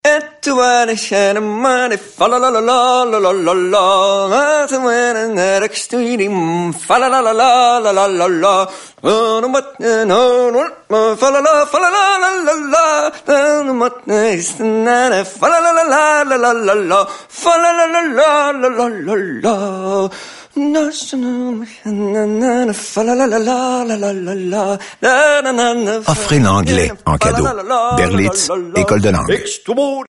SilverRadio - Campaign